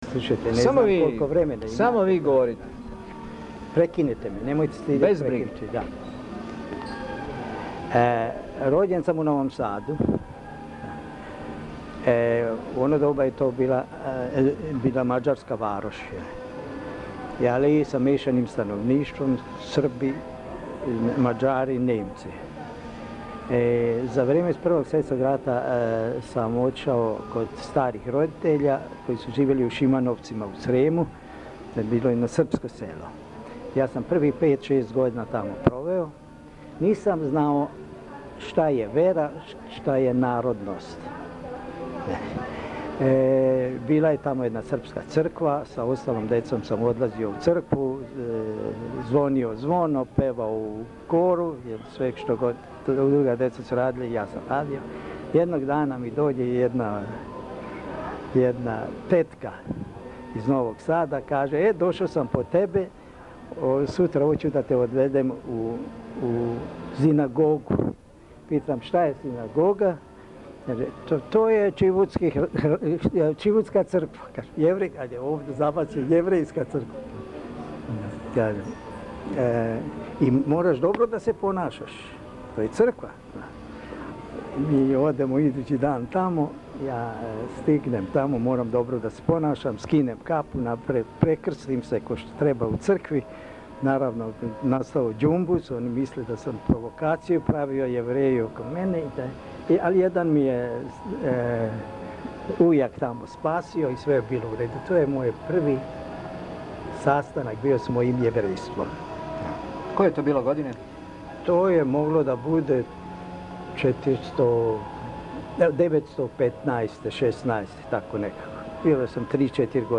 Tako sada slušate samo ono što je zabeleženo na prvoj traci.